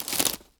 wood_tree_branch_move_02.wav